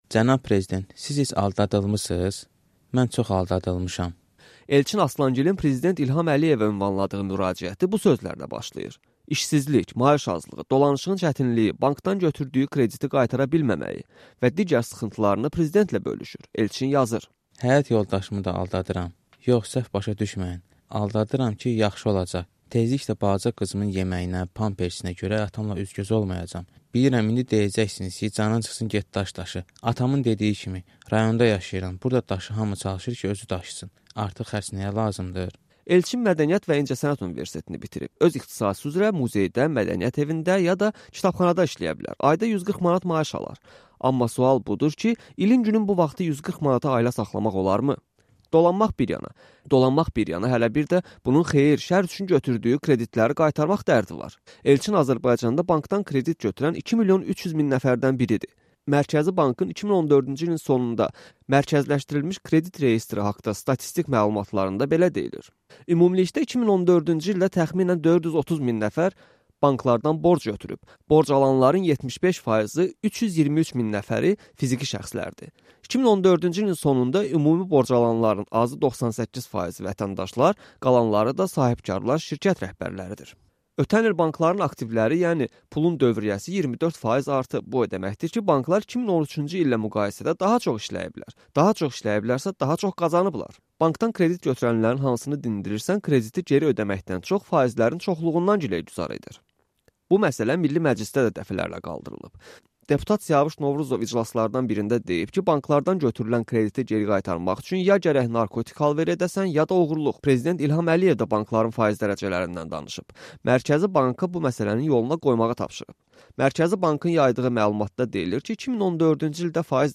Azərbaycan banklarının kredit faizləri barədə traflı reportajı burada dinlə